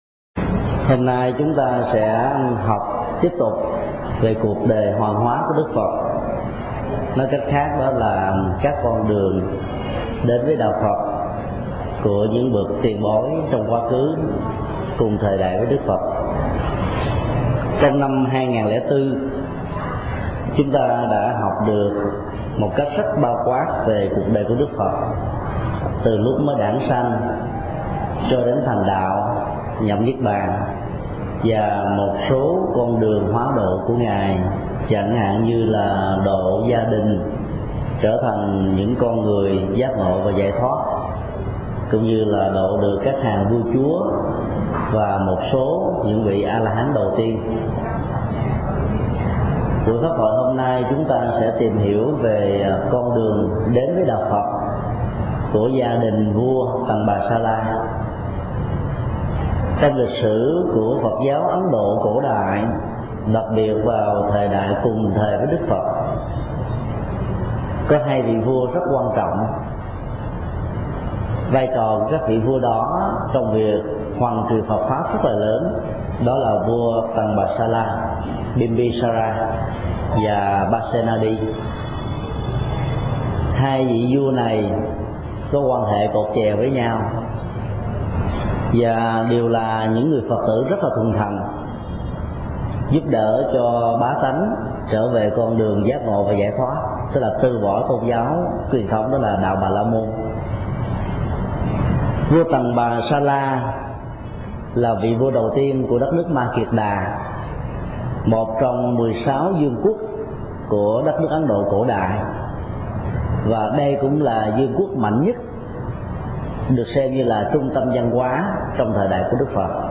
Mp3 Thuyết Giảng Phật độ gia đình Tần-bà-sa-la – Thầy Thích Nhật Từ Giảng tại chùa Giác Ngộ, ngày 1 tháng 5 năm 2005